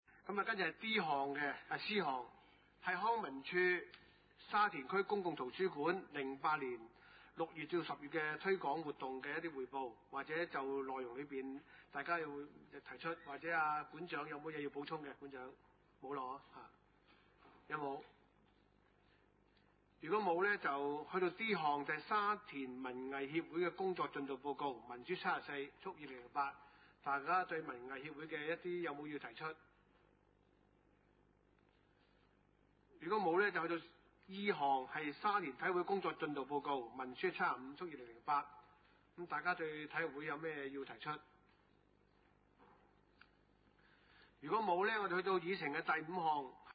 地點：沙田區議會會議室